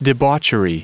Prononciation du mot debauchery en anglais (fichier audio)
Prononciation du mot : debauchery
debauchery.wav